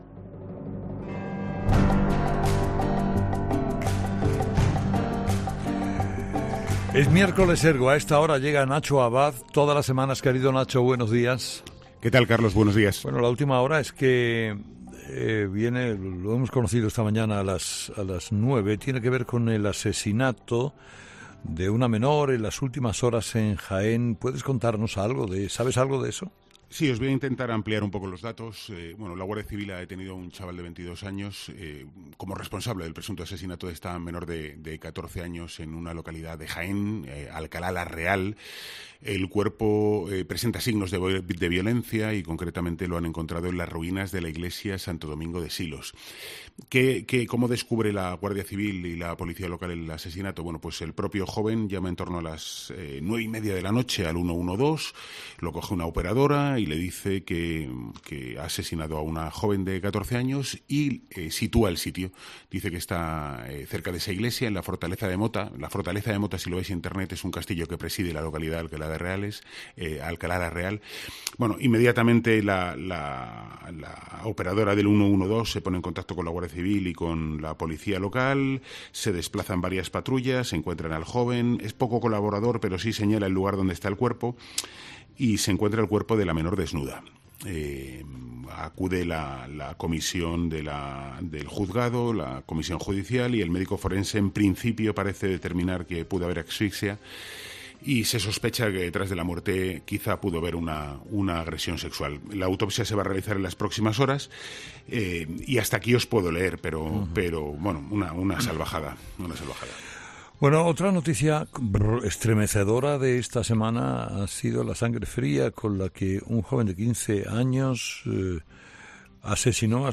Últimas Noticias/Entrevistas
Entrevistado: "Nacho Abad"